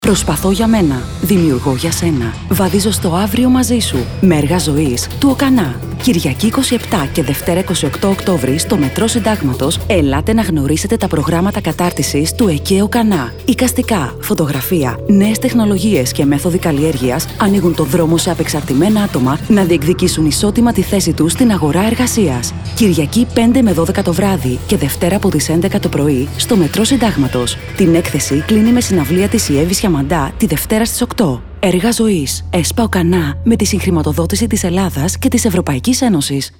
Ραδιοφωνικό μήνυμα